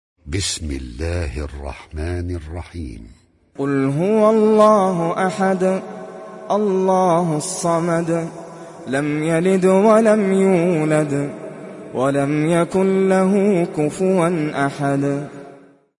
Sourate Al Ikhlas Télécharger mp3 Nasser Al Qatami Riwayat Hafs an Assim, Téléchargez le Coran et écoutez les liens directs complets mp3